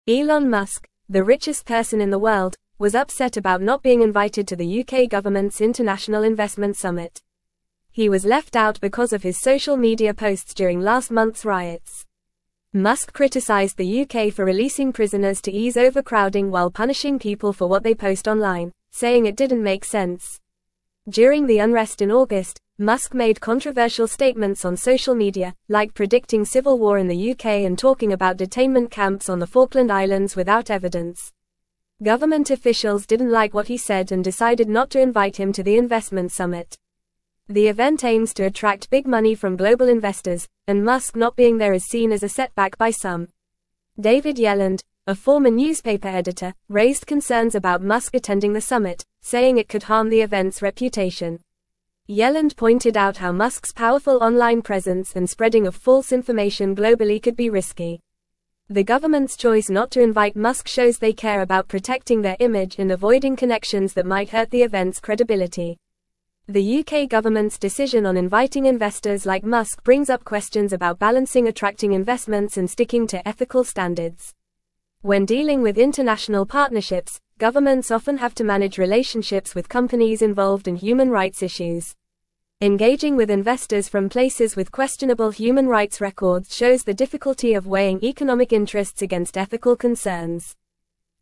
Fast
English-Newsroom-Upper-Intermediate-FAST-Reading-Elon-Musk-Excluded-from-UK-Investment-Summit-Over-Posts.mp3